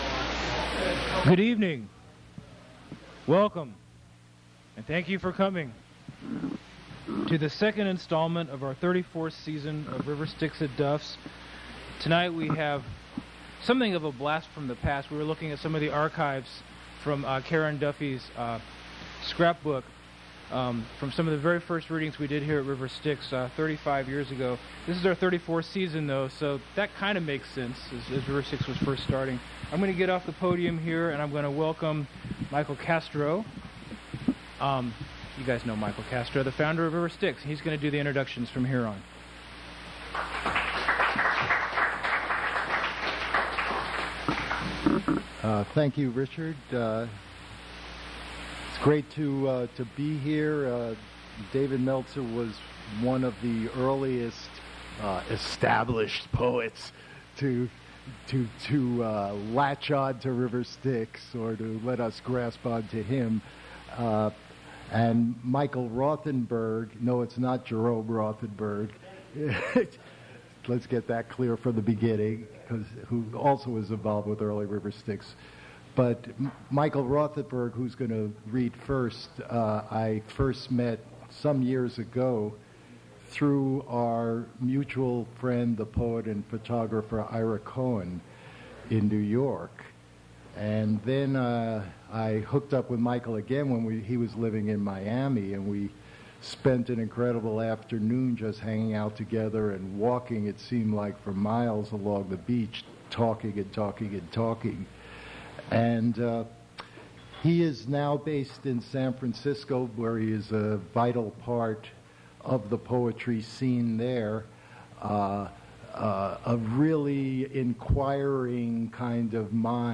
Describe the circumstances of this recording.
mp3 edited access file was created from unedited access file which was sourced from preservation WAV file that was generated from original audio cassette. audio is pretty scratchy; audio abruptly cuts off, can't tell if he was done or not